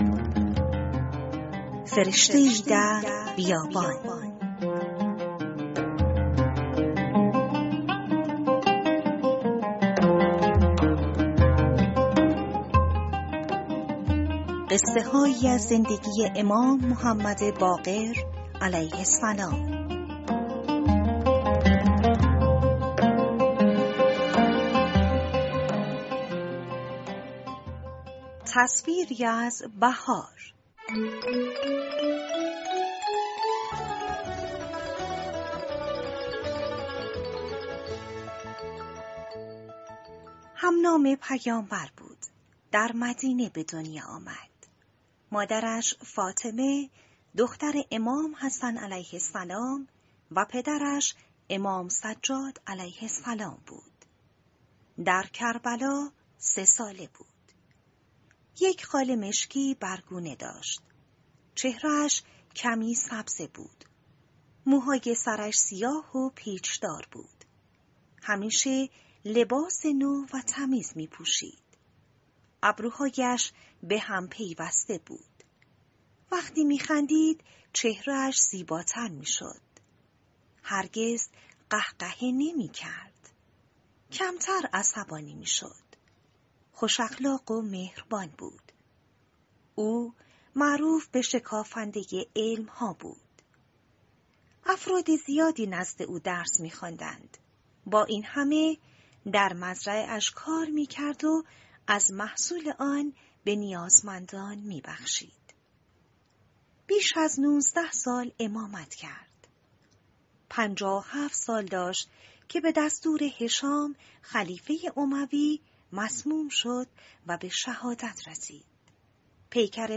کتاب صوتی « فرشته‌ای در بیابان
# قصه کودک # اهل بیت # تربیت فرزند # کلیپ صوتی # کتاب صوتی